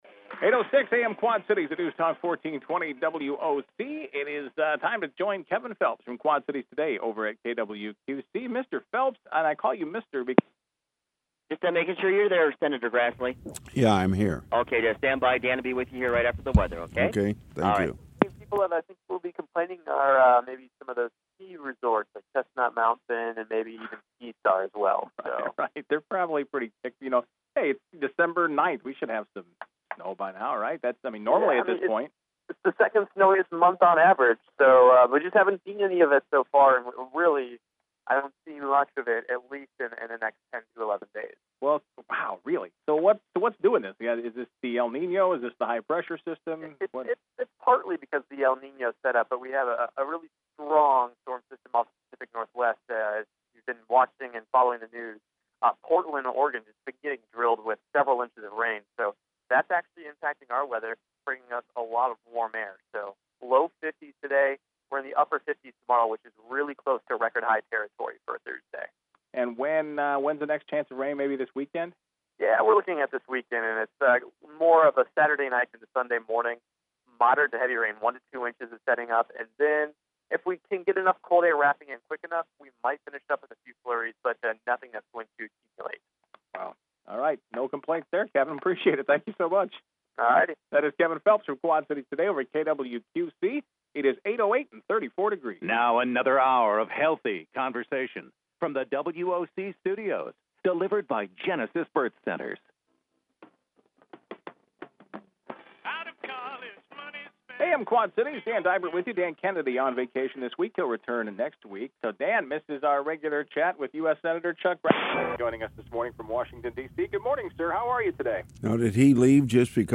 Grassley Live on WOC